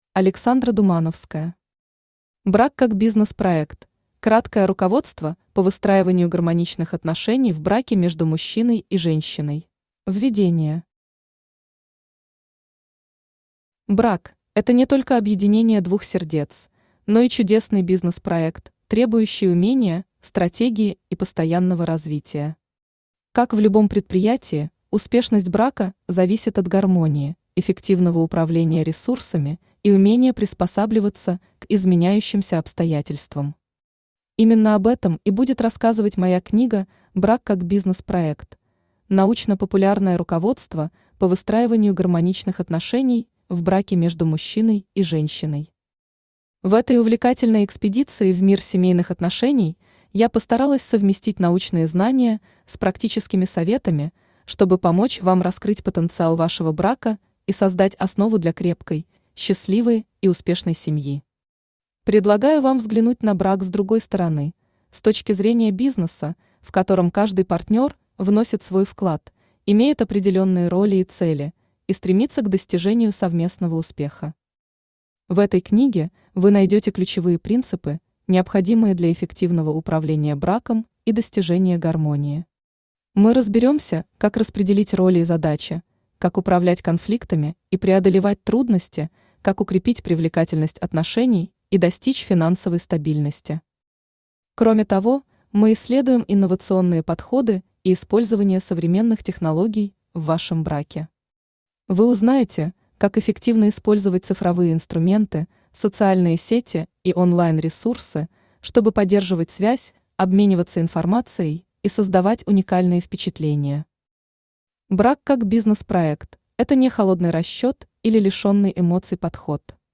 Аудиокнига Брак как бизнес-проект: краткое руководство по выстраиванию гармоничных отношений в браке между мужчиной и женщиной | Библиотека аудиокниг
Aудиокнига Брак как бизнес-проект: краткое руководство по выстраиванию гармоничных отношений в браке между мужчиной и женщиной Автор Александра Думановская Читает аудиокнигу Авточтец ЛитРес.